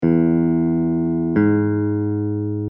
In the diagrams below, we are jumping from a note on an open string (any string will do) to another note on the same string.
Major Third = 2 steps
major-3rd.mp3